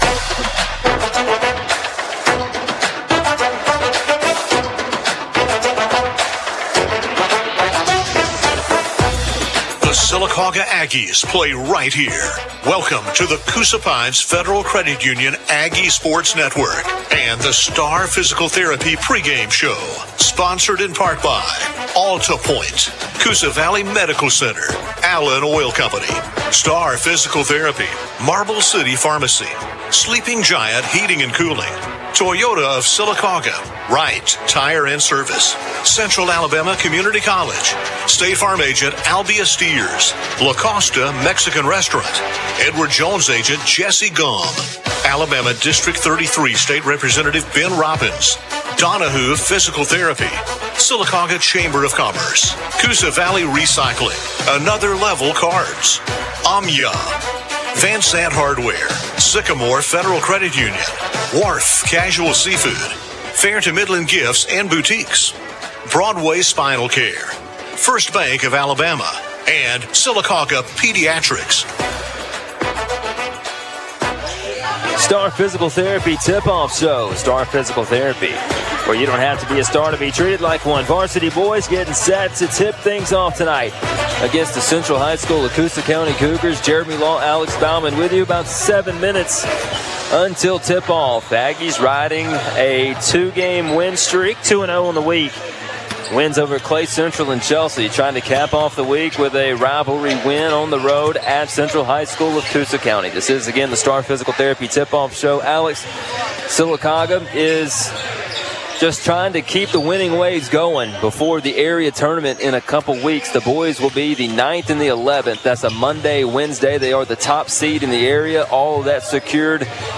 (Boys Basketball) Sylacauga vs. Coosa Central